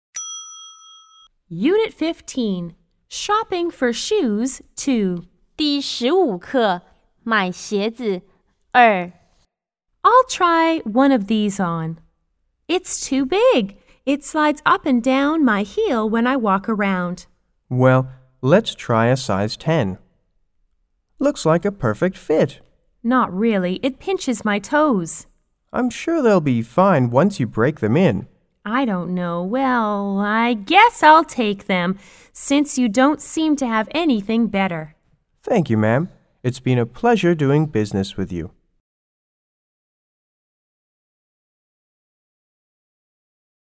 C= Customer S= Salesperson